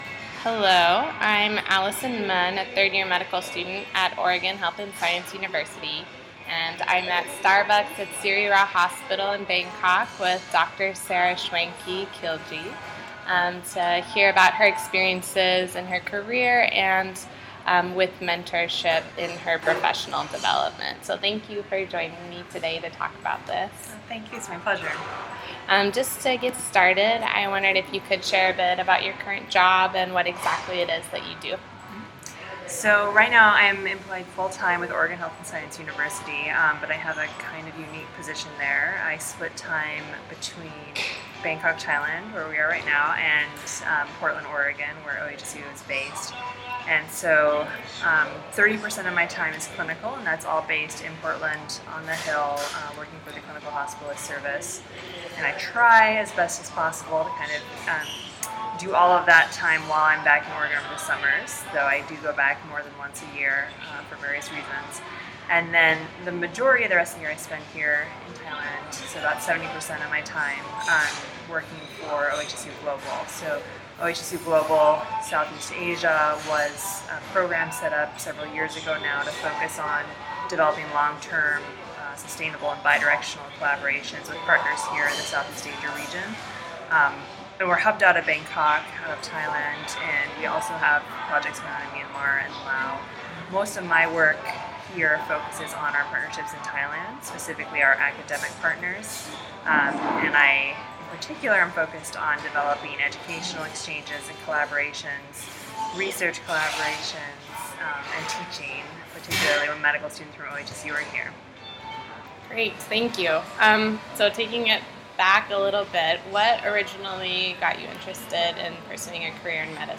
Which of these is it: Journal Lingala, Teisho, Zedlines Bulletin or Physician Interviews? Physician Interviews